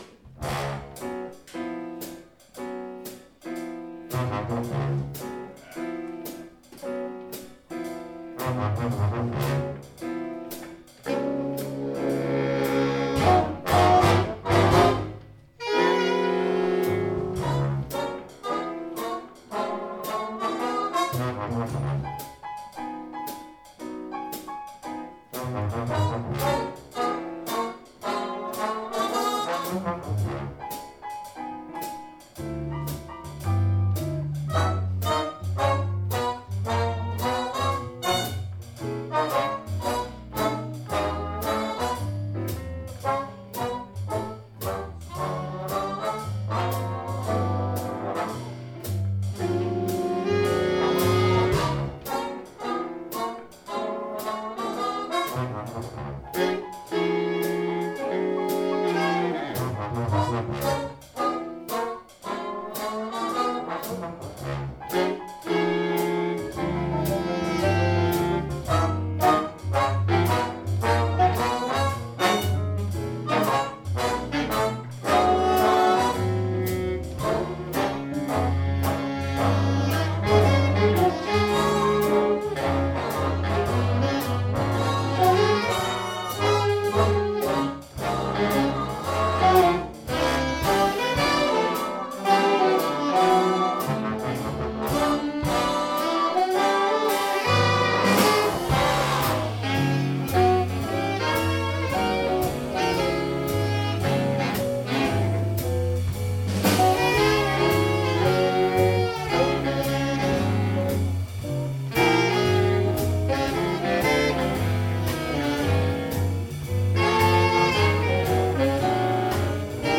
- Hayburner Big Band 26. oktober 2014
Introduktion af nummeret